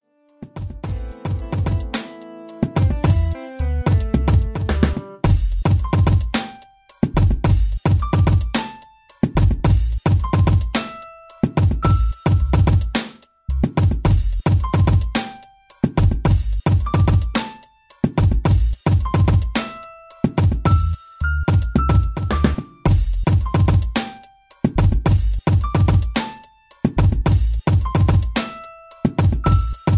R&B Mid tempo grove with a swing beat